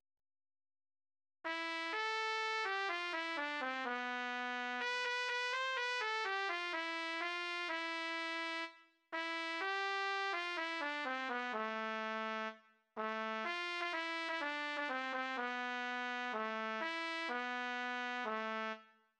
Dichter: (???) / Komponist: (???)